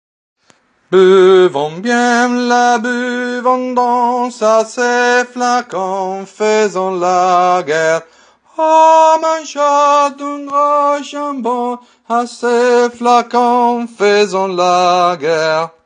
TENORI